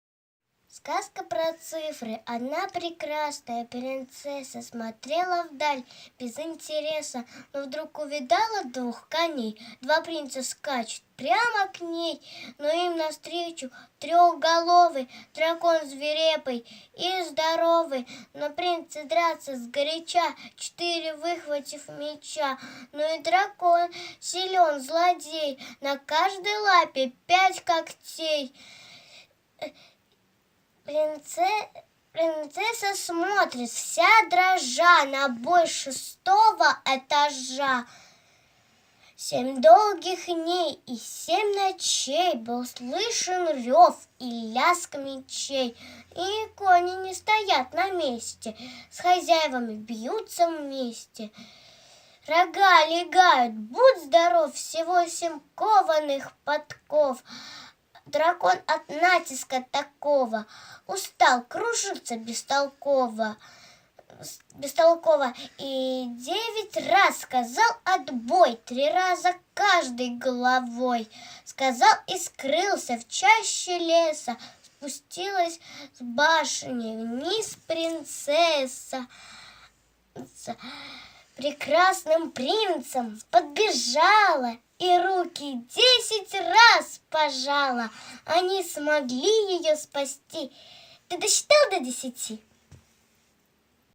Аудиосказки «Математика для малышей"